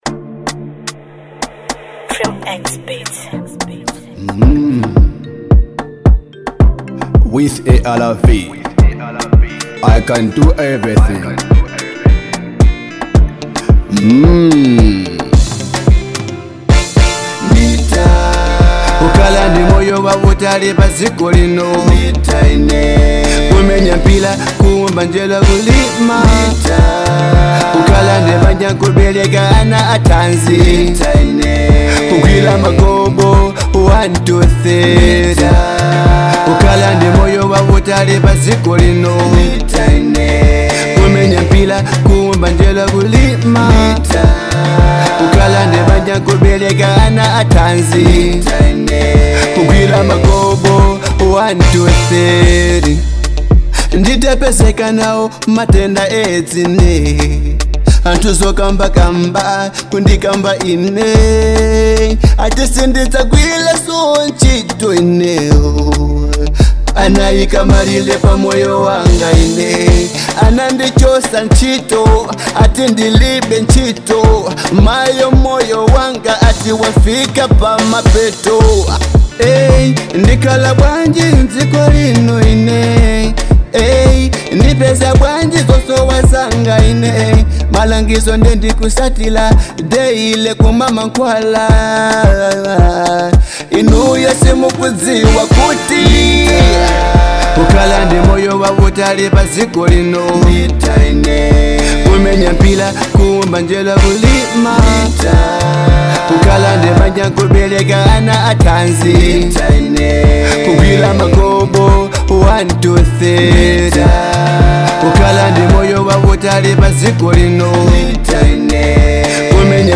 Genre : Afro-Pop